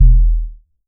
BASS 4.wav